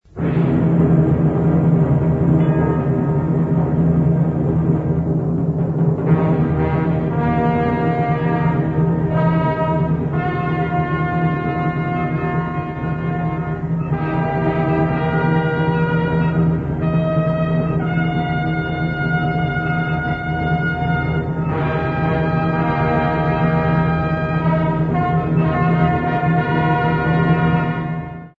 sound track 32, διάρκεια 28'', μουσική και καμπάνες